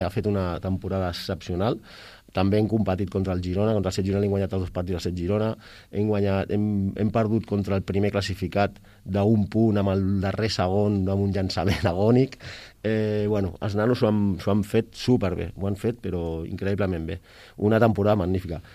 en declaracions a Ràdio Palamós